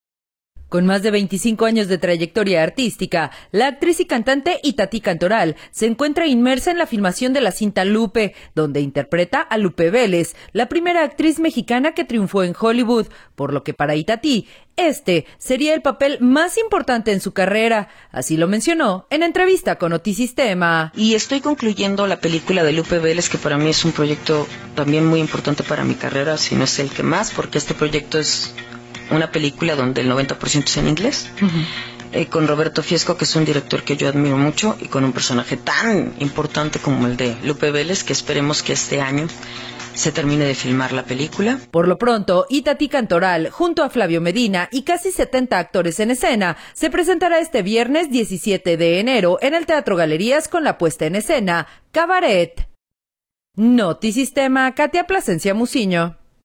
Con más de 25 años de trayectoria artística, la actriz y cantante Itatí Cantoral, se encuentra inmersa en la filmación de la cinta “Lupe” donde interpreta a Lupe Vélez, la primera actriz mexicana que triunfó en Hollywood, por lo que, para Itatí, este sería el papel más importante en su carrera, así lo mencionó en entrevista con Notisistema: